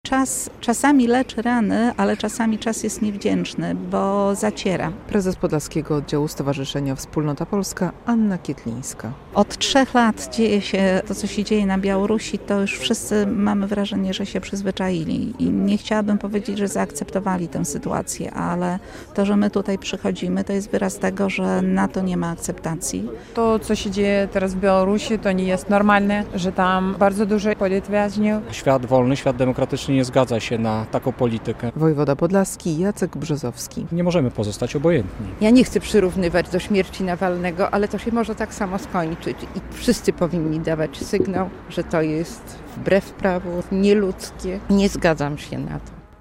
relacja
Podczas akcji zorganizowanej przez Związek Polaków na Białorusi głos zabrał też wojewoda podlaski Jacek Brzozowski, podkreślając, że w demokratycznym świecie nie ma zgody na politykę Aleksandra Łukaszenki zarówno wobec polskiej mniejszości jak i obywateli Białorusi.